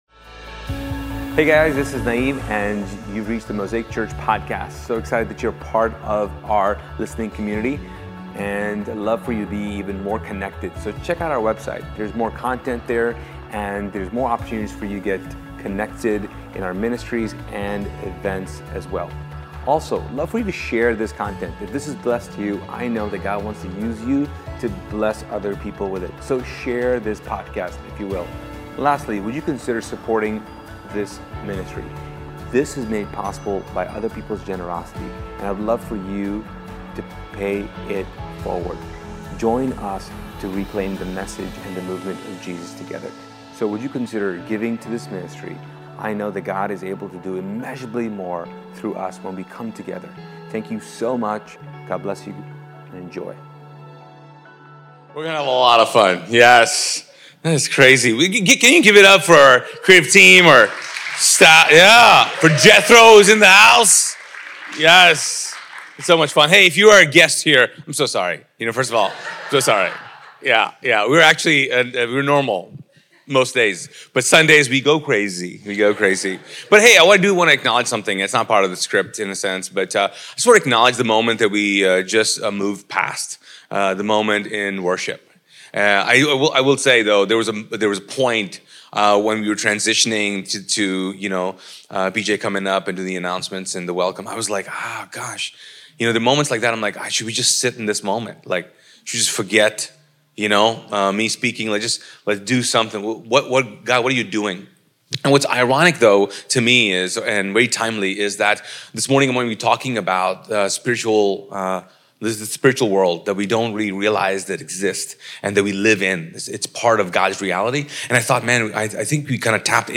Using Luke 2, this sermon will help you throw off the labels of not being good enough, smart enough, or powerful enough by realizing that there is an alternate universe at play for your self-perception and spirituality. Equip yourself with all the tools from Ephesians 6 so you can fight back the supernatural darkness.